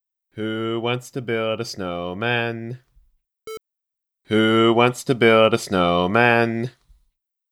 I'm not sure how to fix this hum issue. Despite the large amount of noise reduction I apply, there's still a lot of hum.
That sounds much better: no distortion.
As-is that’s better than most YouTube audio.
I’m deducting points for excessive sibilance, (curable with de-essing), and reverberation from the room, which can be reduced, (but not cured), with an expander like couture .